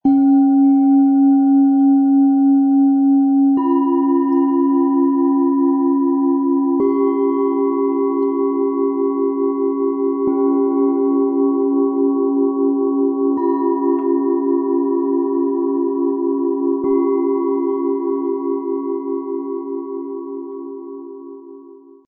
Wärme, Ruhe und freundliche Leichtigkeit - dieses Set entfaltet eine tiefe, tragende Klangqualität, die entspannt und langsam nach oben öffnet.
Die größte Schale schenkt beruhigenden, vollen Klang - harmonisch und ausgleichend.
Die mittlere Schale klingt warm, kraftvoll und freundlich.
Die kleinste Schale bringt eine leichte, helle Energie ins Set - wie ein freundliches Lächeln zum Ausklang.
Ein voller, tragender Klang. Er bringt Stille, Gelassenheit und innere Weite.
Ein warmer, lebendiger Ton. Er kräftigt die Mitte und wirkt offen und nahbar.
Ein feiner, freundlicher Klang. Er hellt auf, wirkt spielerisch und lädt zum Loslassen ein.
Der Klang trägt - warm, harmonisch und mit einem freundlichen Ausklang.
Er bringt die Schalen weich, ruhig und voll zum Schwingen.
Bengalen Schale, Matt-Glänzend, 16,1 cm Durchmesser, 7,9 cm Höhe